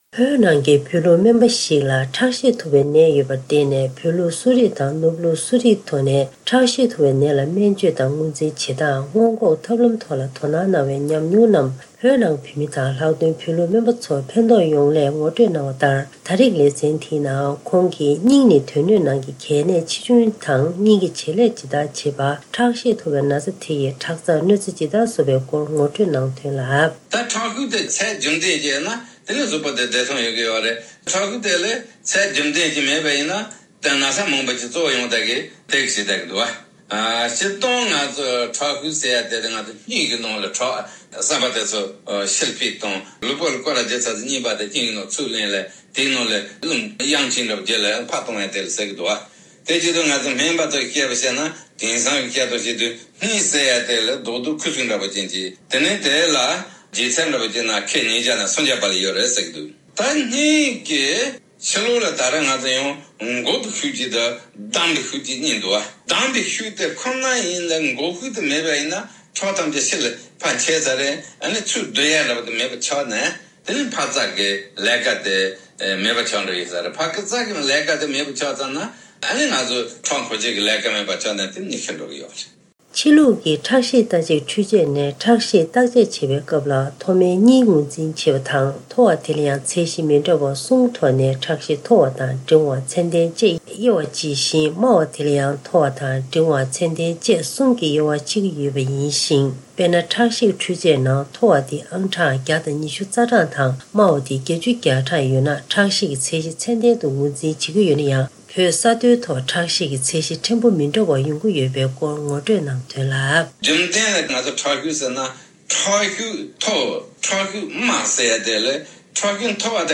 སྒྲ་ལྡན་གསར་འགྱུར། སྒྲ་ཕབ་ལེན།
བོད་ནས་སྨན་པ་ཞིག་གིས་ངོ་སྤྲོད་གནང་བ་